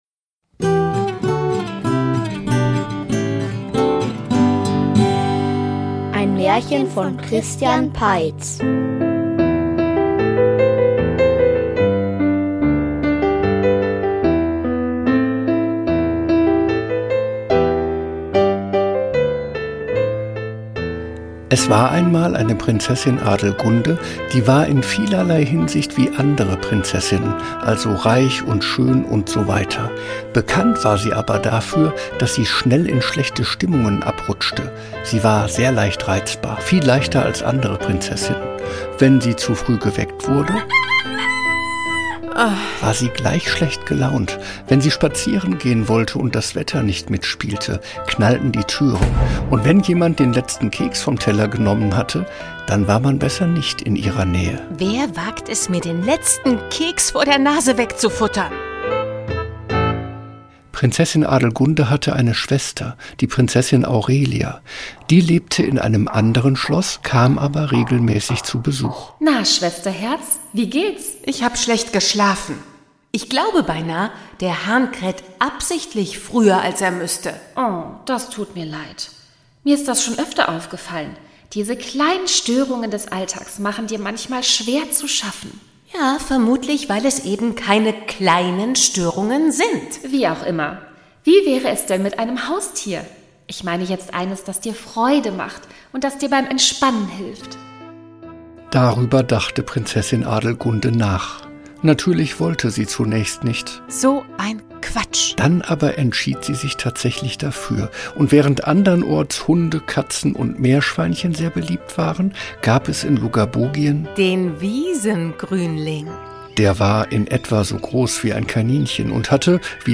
Die Wut des Drachen --- Märchenhörspiel #51 ~ Märchen-Hörspiele Podcast